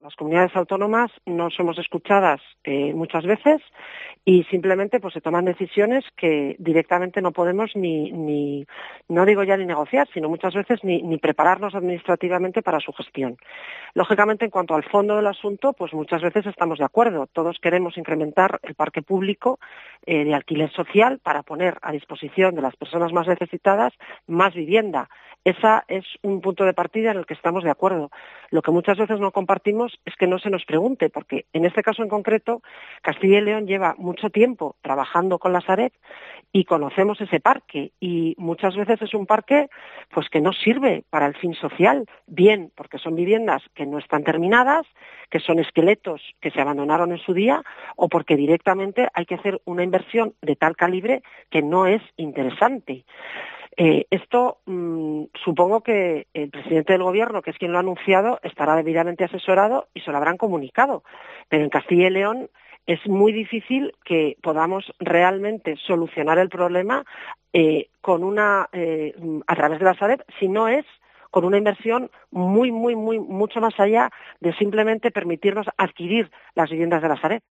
En declaraciones a COPE, Pardo ha explicado que parte de estas viviendas “no están terminadas y son esqueletos que se abandonaron en su día”.